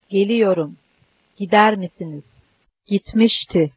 In verb conjugations, the accent may sometimes fall on a middle syllable